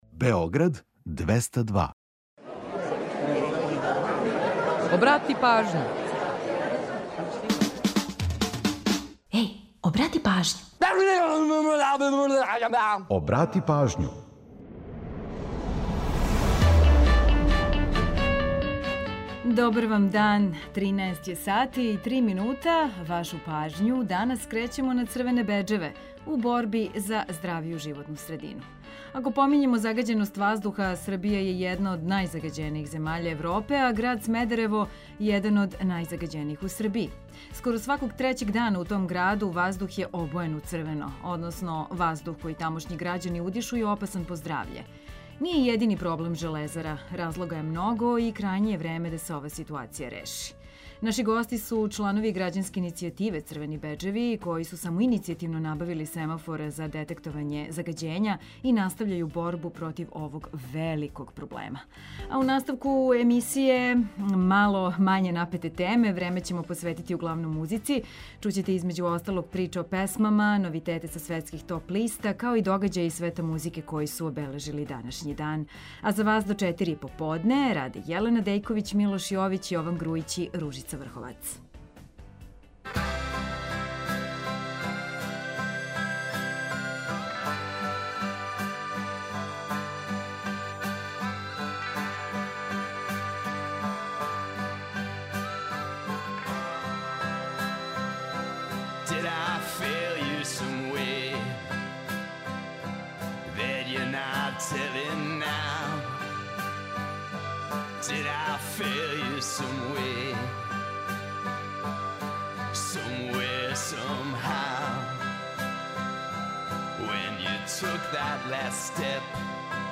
Наши гости су чланови грађанске иницијативе „Црвени беџеви” који су самоиницијативно набавили семафор за детектовање загађења и настављају борбу против овог великог проблема. У наставку програма, време посвећујемо, углавном, музици.